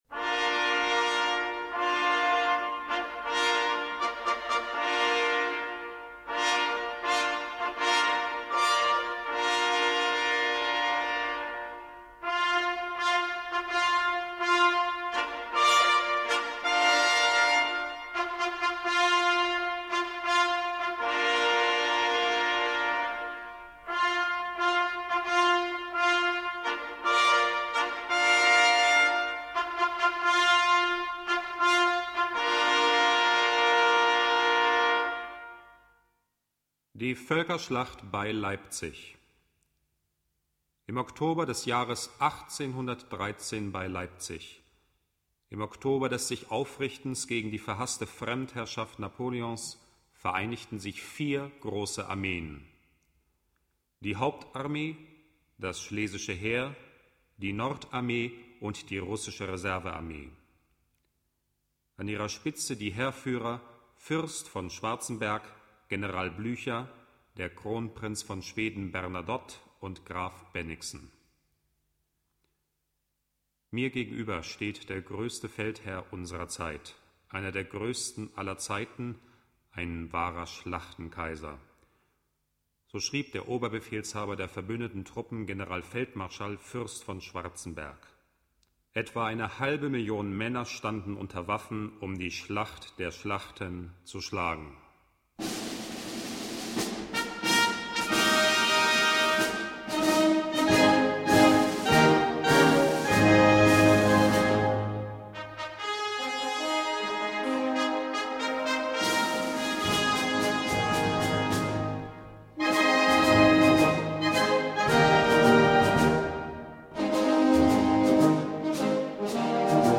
Gattung: Dokumentation in 4 Sätzen
Besetzung: Blasorchester